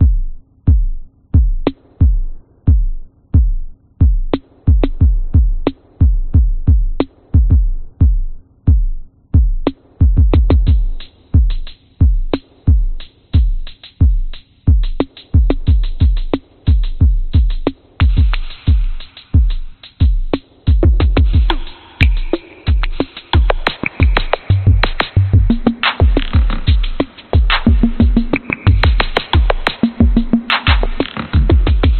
Tag: 节拍 循环 鼓声